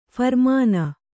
♪ pharmāna